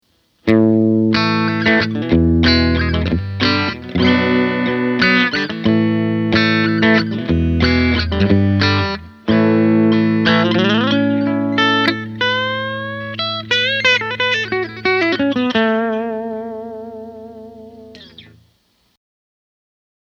In any case, here are six versions of the same phrase with each different configuration:
Bridge 2